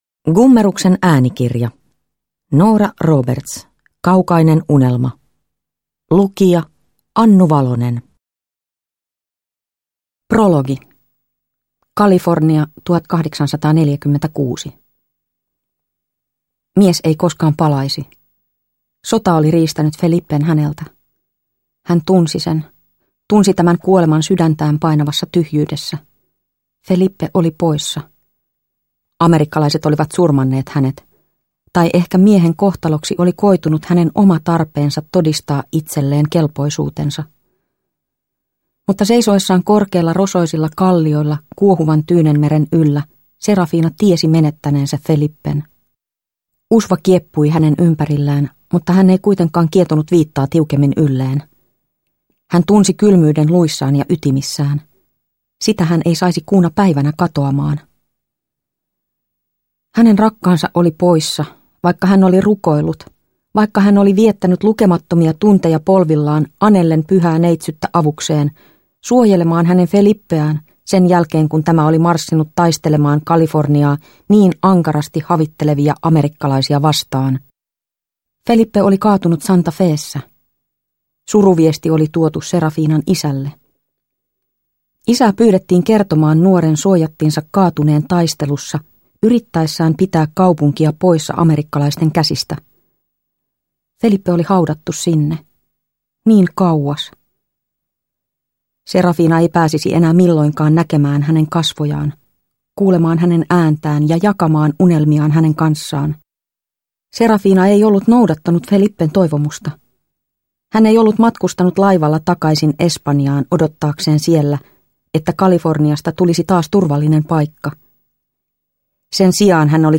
Kaukainen unelma – Ljudbok – Laddas ner